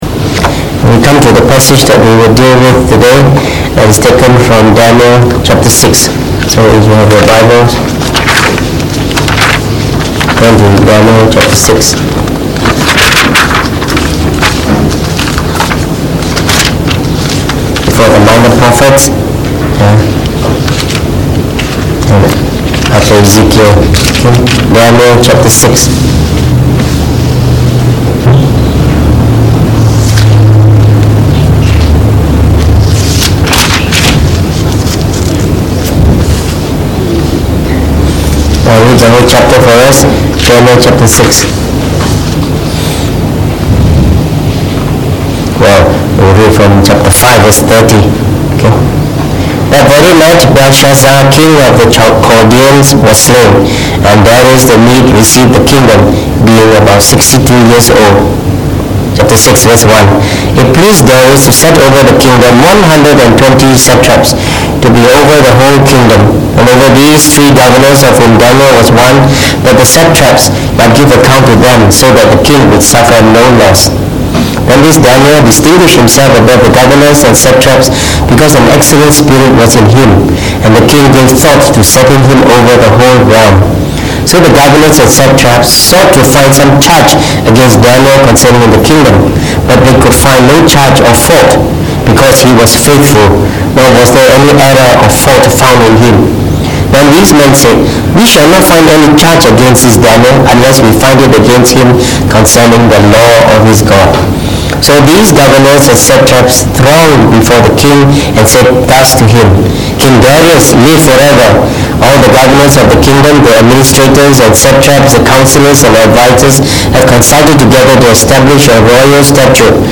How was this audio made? Preached on the 30th of December 2018.